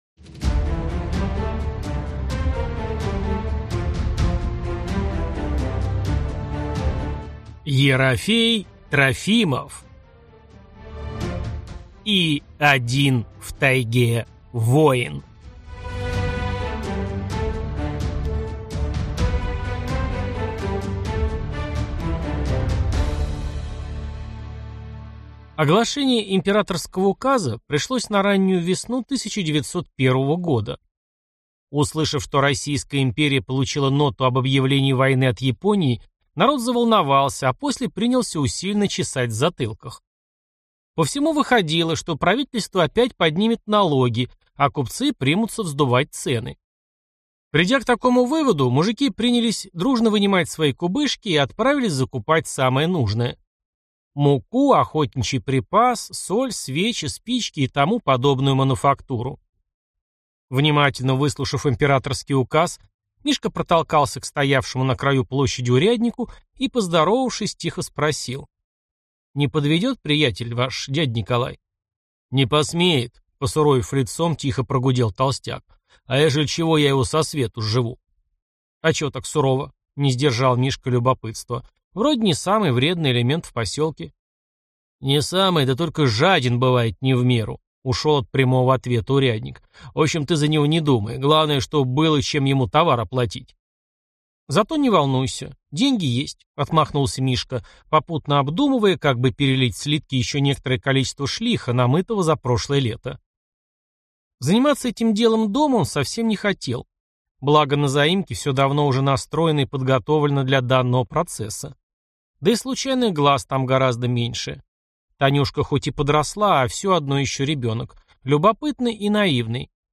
Аудиокнига И один в тайге воин | Библиотека аудиокниг